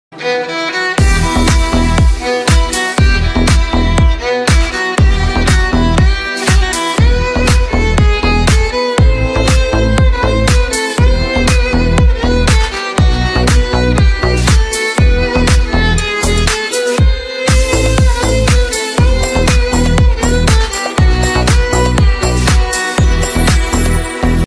موسيقى بالة الكمان
الموسيقية الهادئة